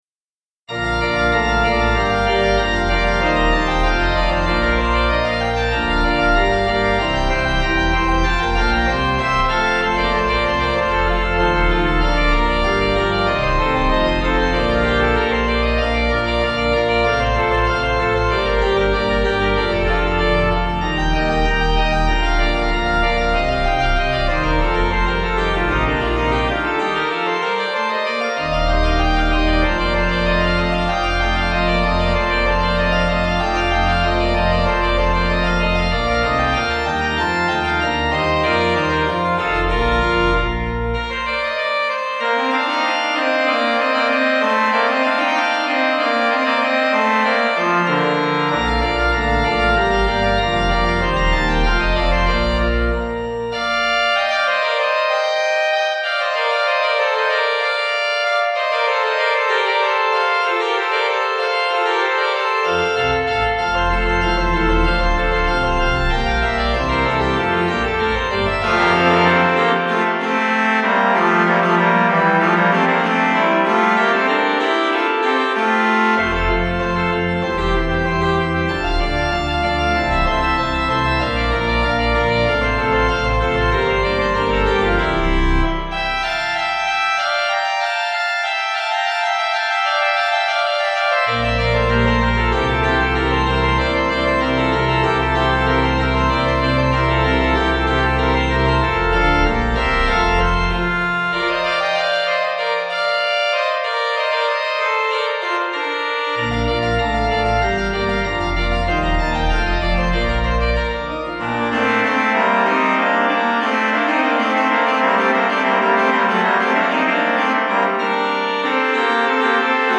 Easy Listening   Bb 188.6kb